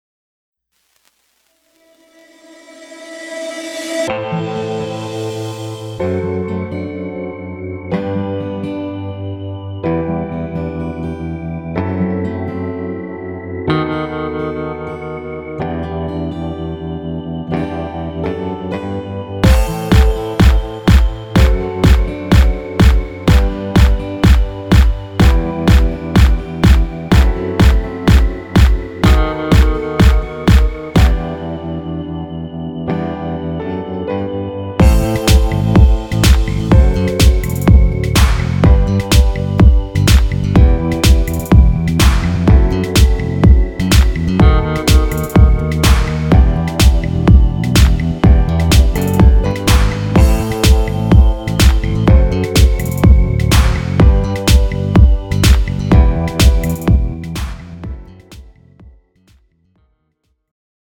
장르 가요 구분